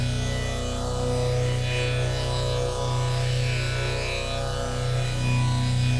Index of /musicradar/dystopian-drone-samples/Non Tempo Loops
DD_LoopDrone4-B.wav